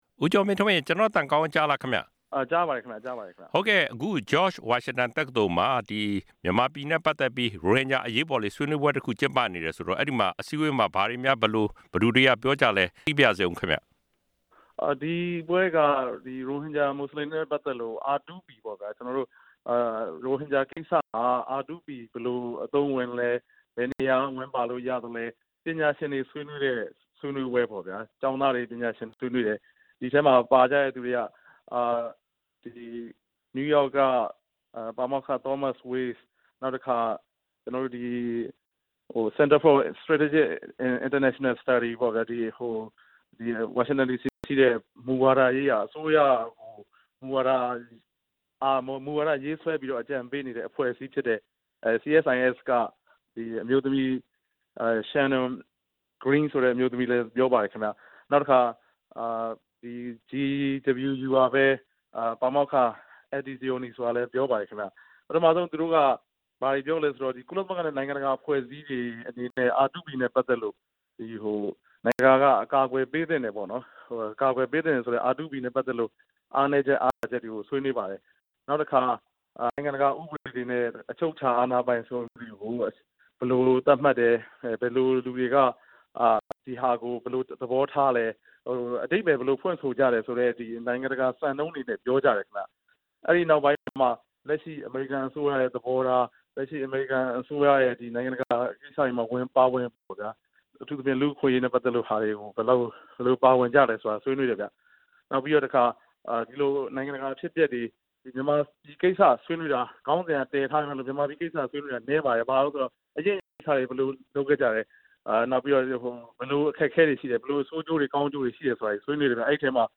ရိုဟင်ဂျာအရေးနဲ့ R2P အလားအလာဆိုင်ရာ ဆွေးနွေးပွဲအကြောင်း မေးမြန်းချက်